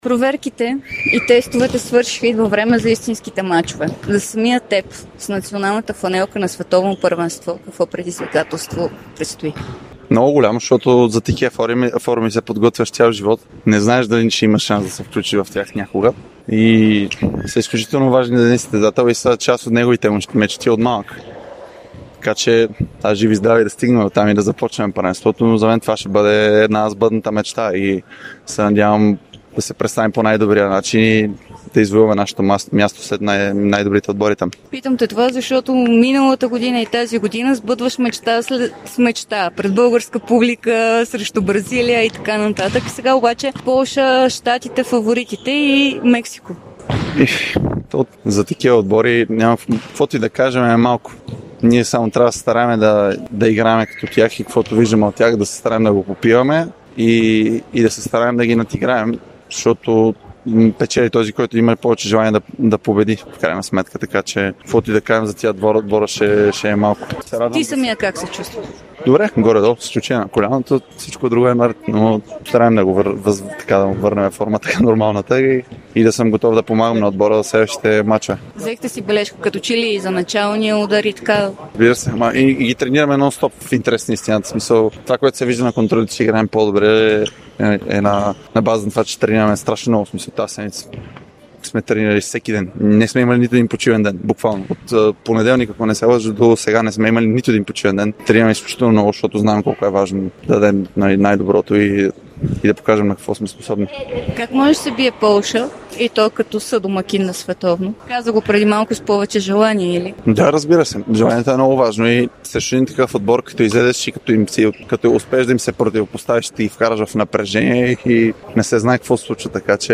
Една от младите надежди в националния отбор по волейбол на България Аспарух Аспарухов даде интервю пред Дарик и dsport, в което говори за сбъдната си мечта, а именно участие на Световното първенство.